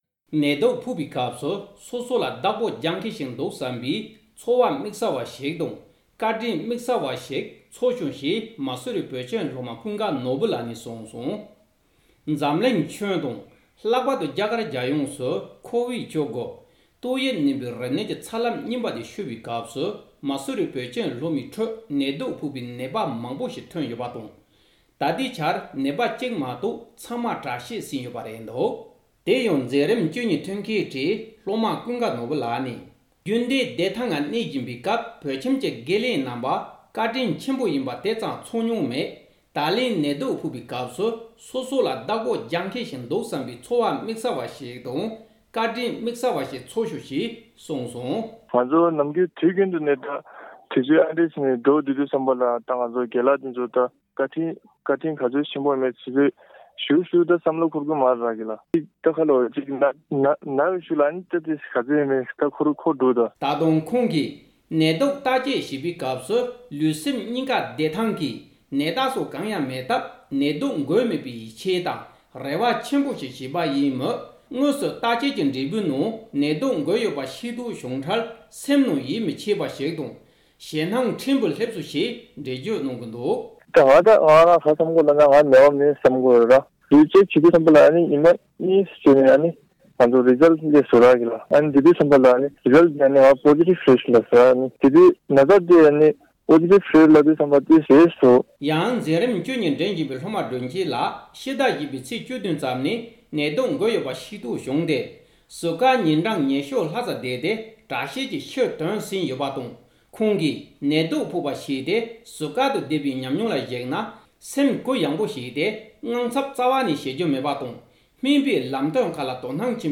ཀོ་ཝིཌ་༡༩་ཏོག་དབྱིབས་རིམས་ནད་འགོས་ནས་དྲག་སྐྱེད་བྱུང་བའི་མ་སུ་རི་བོད་ཁྱིམ་གྱི་སློབ་མ་ཁག་ཅིག་གི་ཉམས་མྱོང་མྱོང་ཚོར་བཅར་འདྲི་བྱས་པ།
སྒྲ་ལྡན་གསར་འགྱུར།